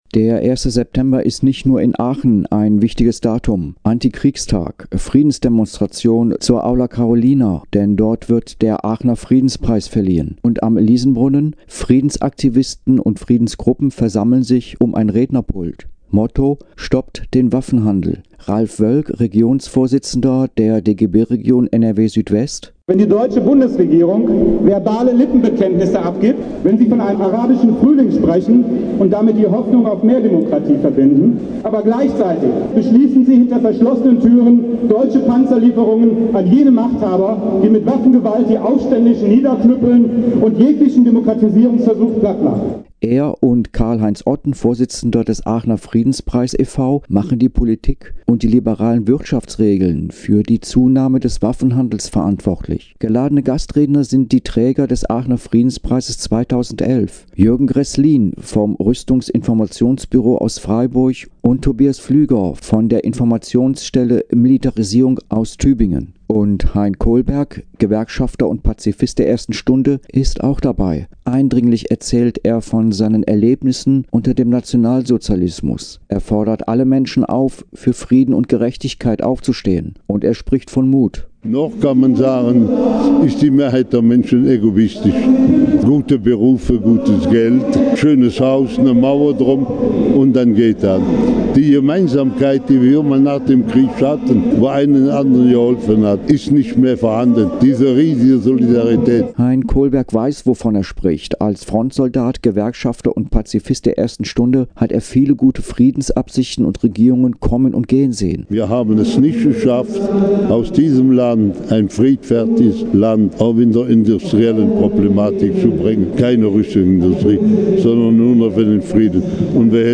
Gebaute Radiofeature
Friedensdemonstration zur Aula Carolina. Denn dort wird der Aachener Friedenspreis verliehen.